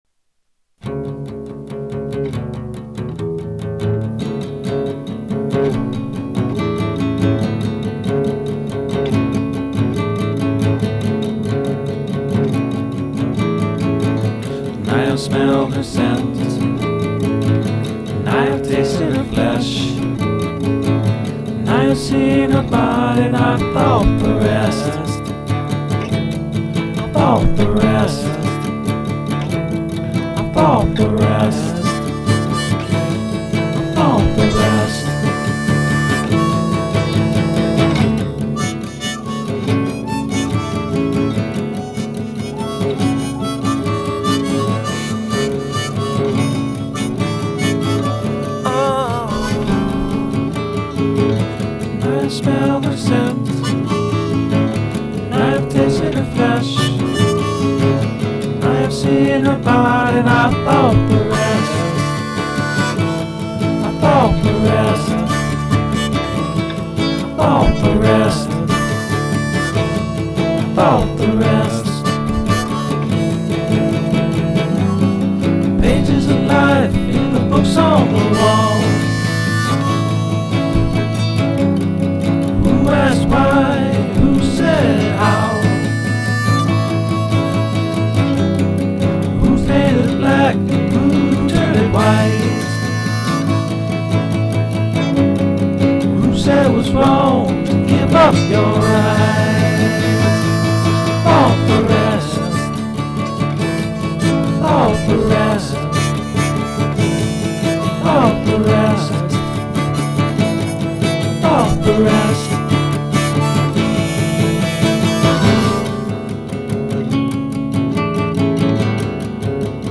Acoustic alternative folk pop.